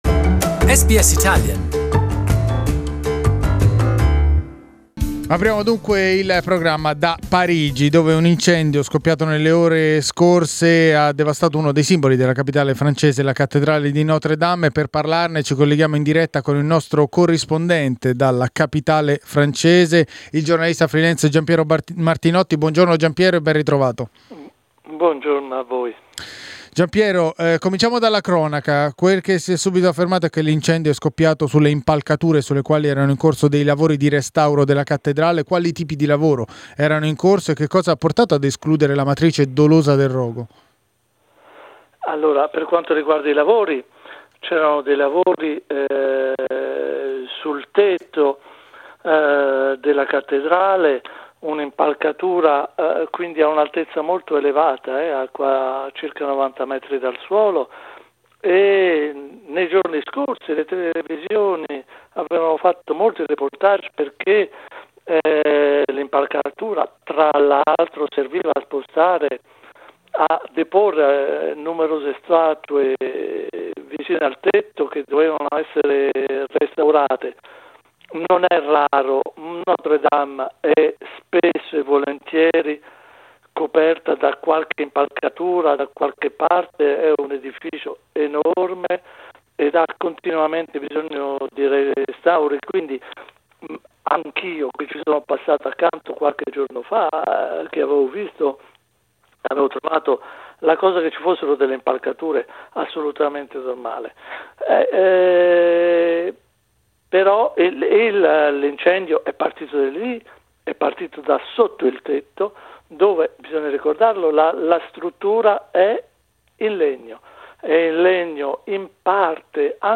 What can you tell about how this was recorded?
Ne abbiamo parlato in diretta